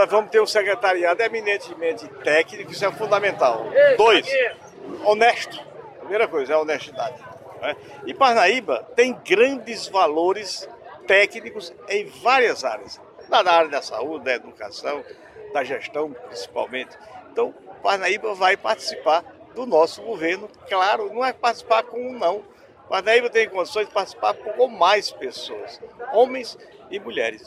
Em entrevista ao Portal Tribuna de Parnaíba, que está cedendo espaço igualitário a todos os candidatos ao governo do Piauí, Elmano falou sobre a possibilidade de um parnaibano integrar sua equipe de gestão caso seja eleito.